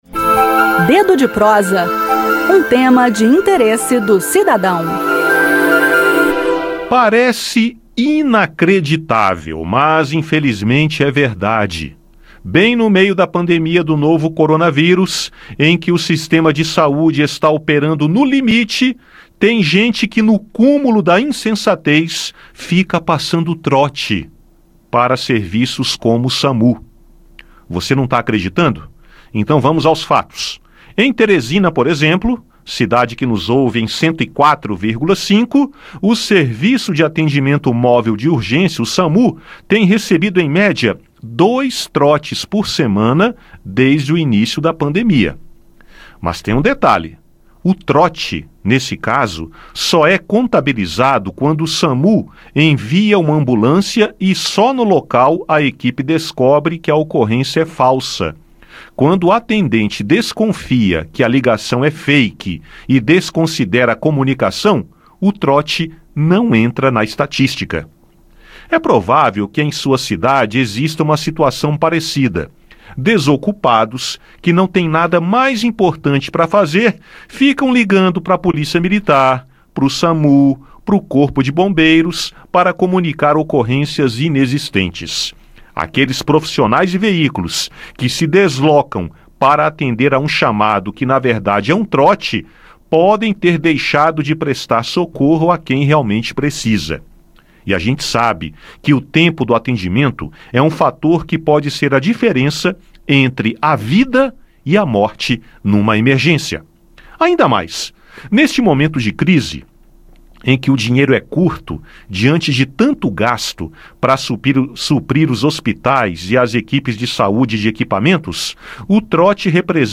Ouça o áudio do bate-papo com o jornalista